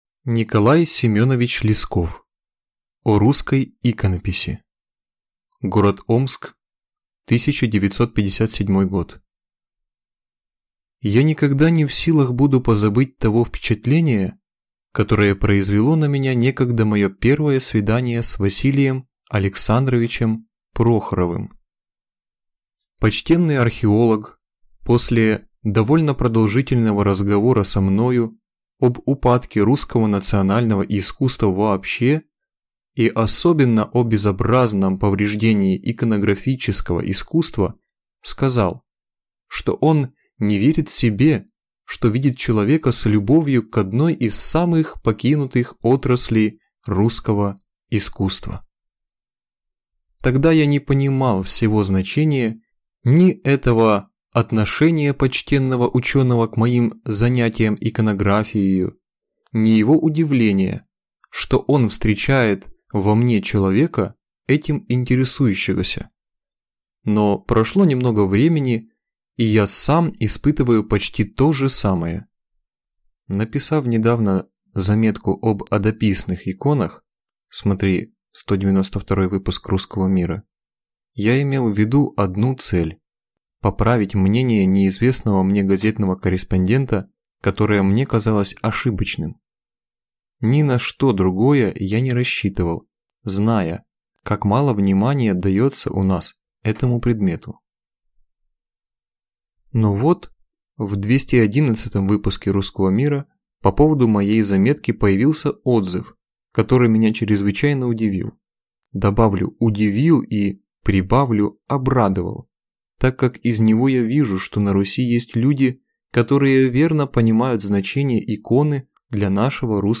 Аудиокнига О русской иконописи | Библиотека аудиокниг